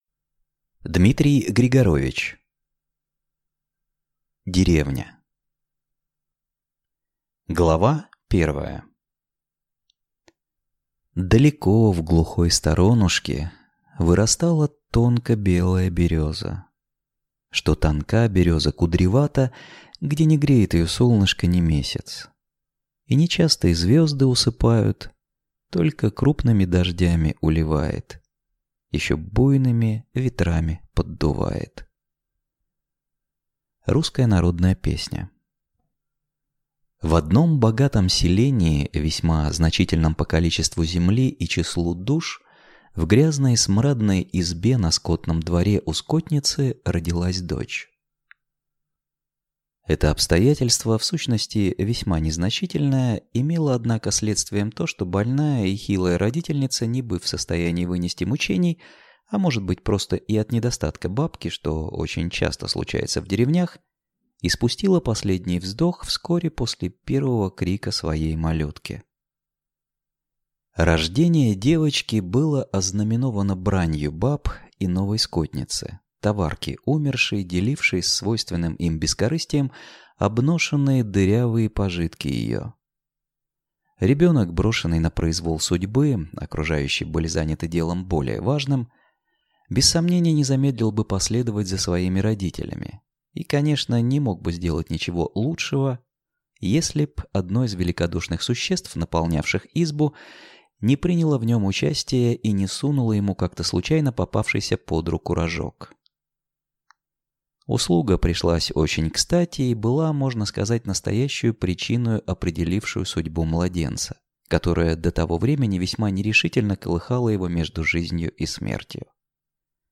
Аудиокнига Деревня | Библиотека аудиокниг